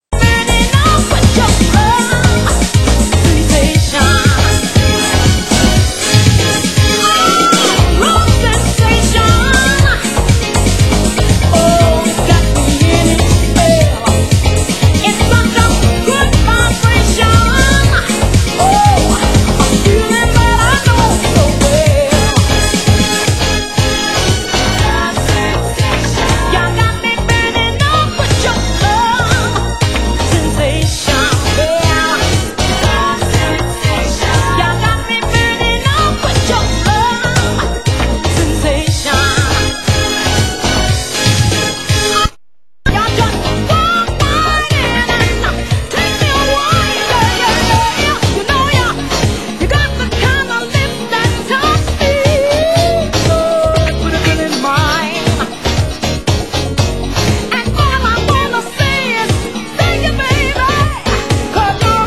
Genre: UK House
club house mix